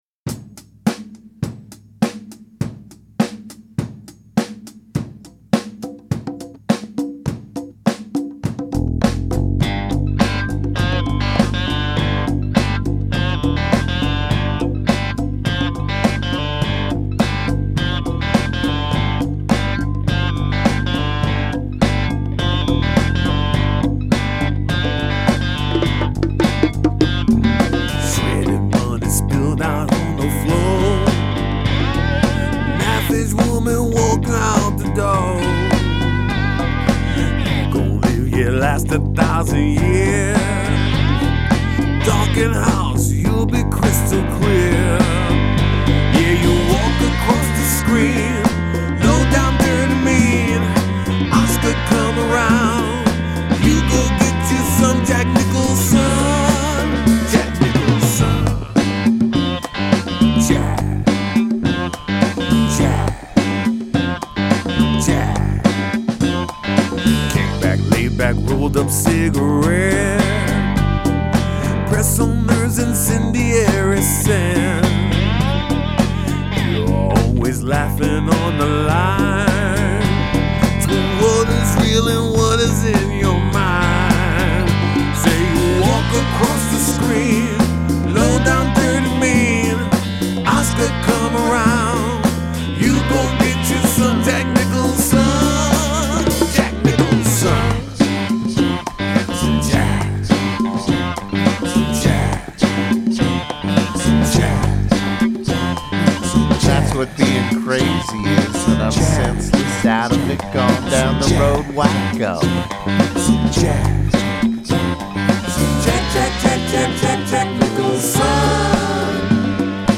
lead vocal
slide guitar